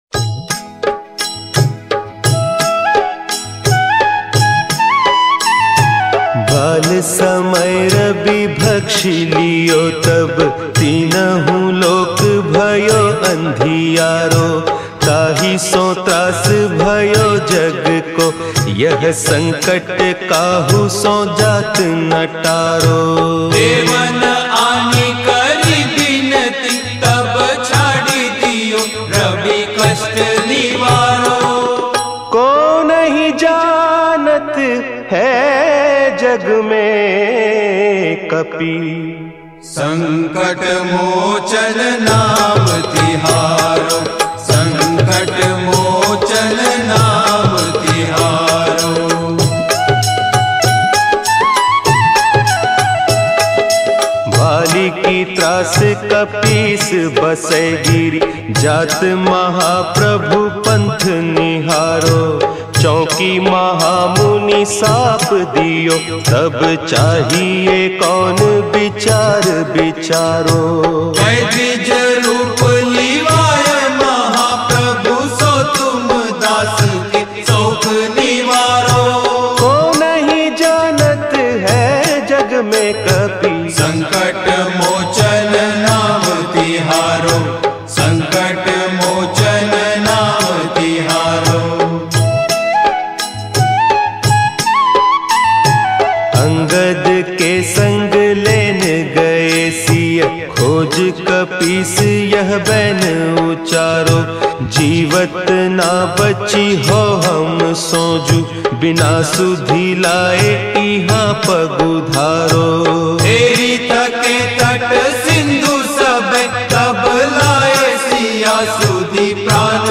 Bhakti